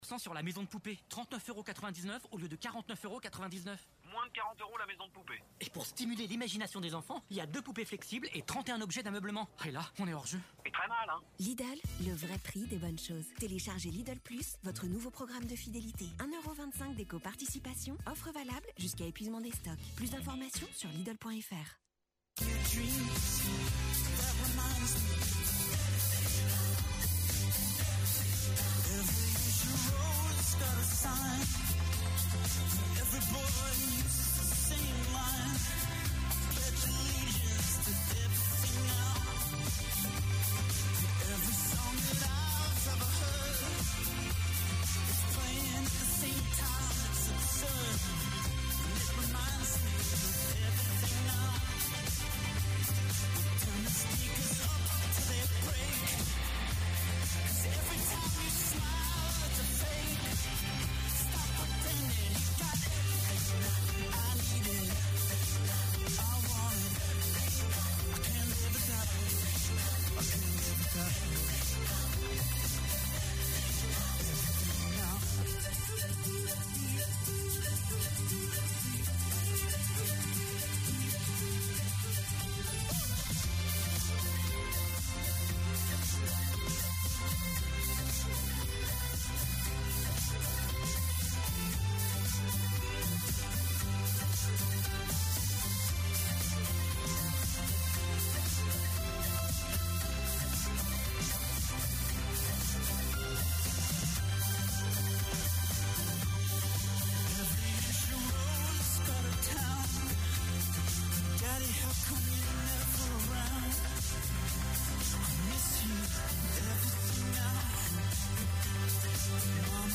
Journal du lundi 22 novembre